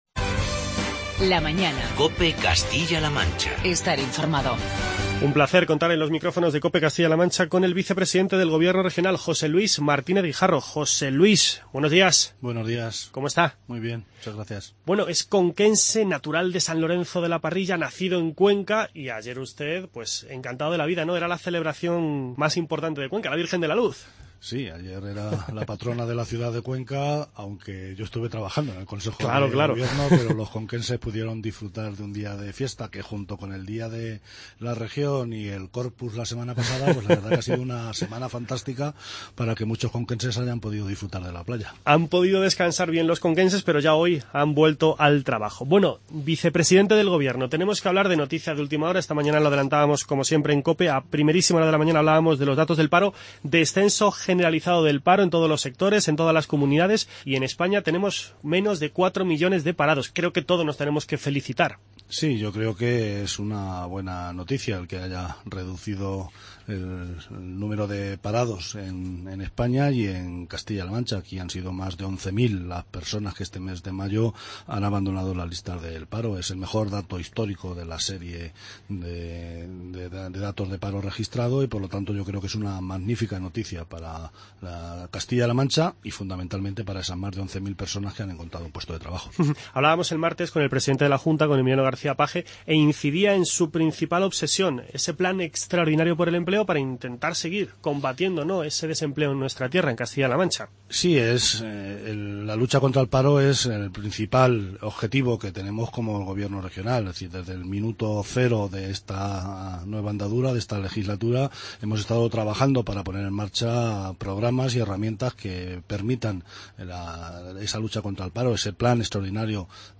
Entrevista con el vicepresidente del Gobierno de Castilla-La Mancha, José Luis Martínez Guijarro.